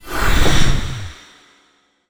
magic_flame_of_light_02.wav